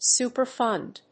/ˌsupɝˈfʌnd(米国英語), ˌsu:pɜ:ˈfʌnd(英国英語)/